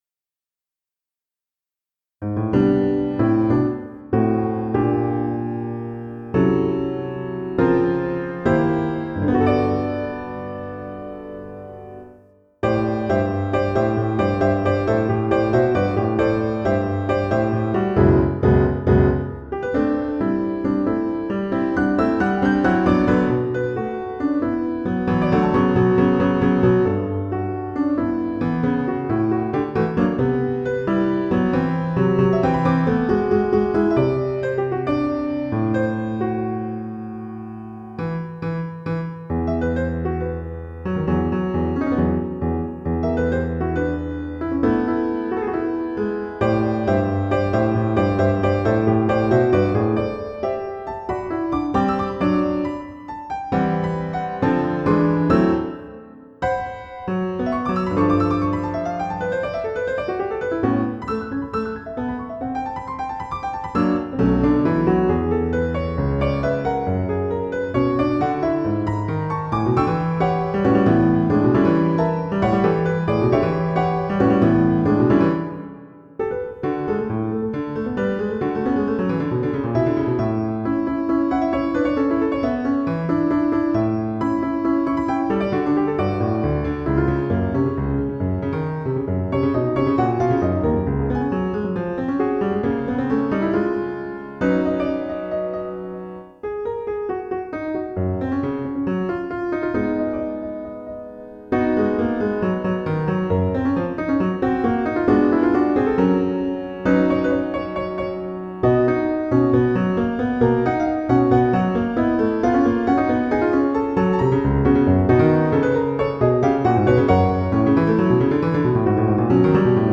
Not Yet Lost (a Piano Sonata)